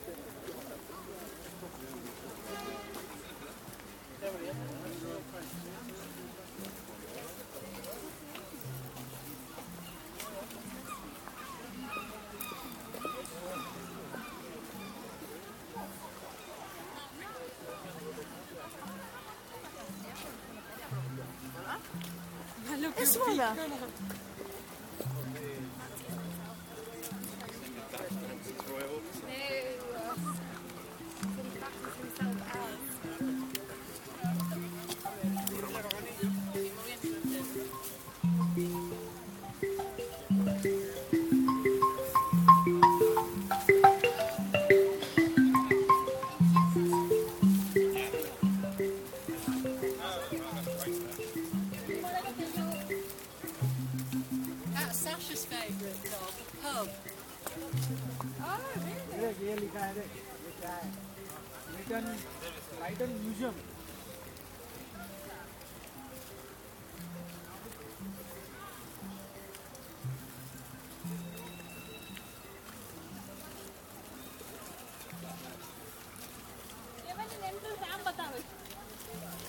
Brighton Pavilion soundscape boo